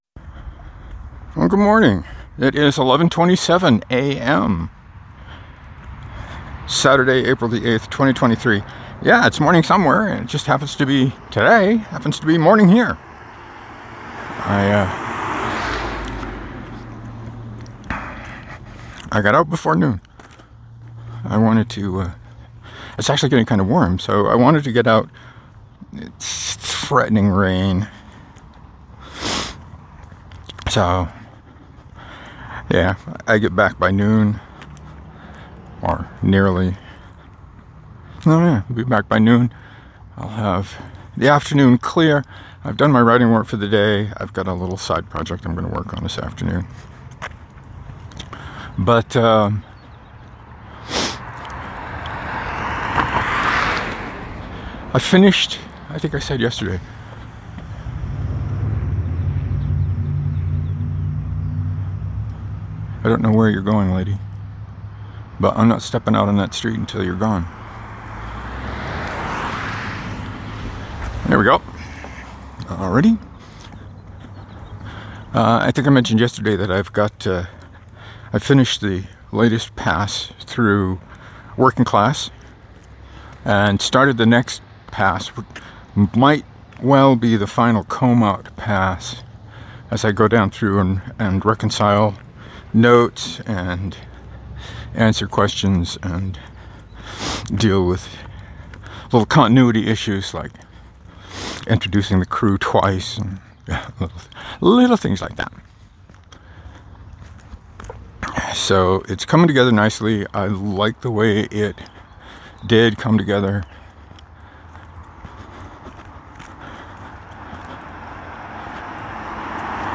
I finally got out early enough to actually have a morning walk again and to talk during it. I talked mostly about where I am with Working Class, Ark Survival, what would be the ideal game for me to play, and why Eve Online wasn’t it.